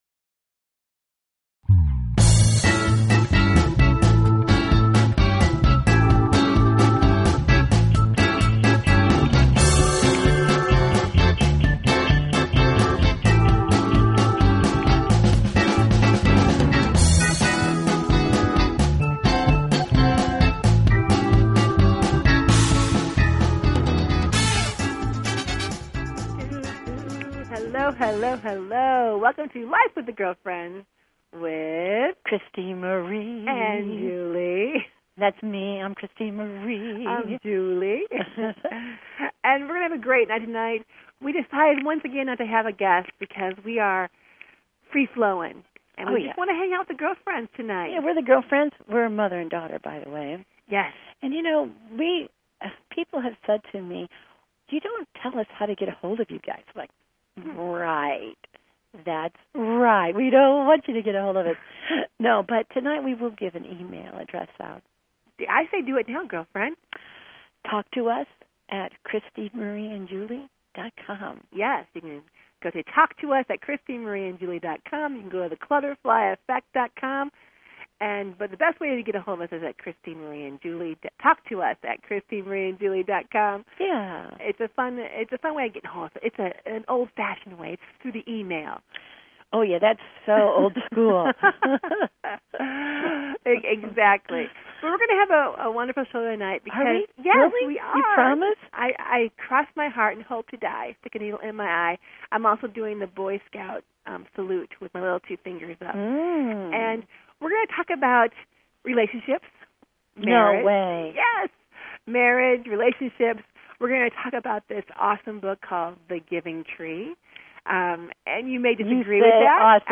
Talk Show Episode, Audio Podcast, Life_With_The_Girlfriends and Courtesy of BBS Radio on , show guests , about , categorized as
And join the girlfriends up close and personal for some daily chat that’s humorous, wholesome, and heartfelt.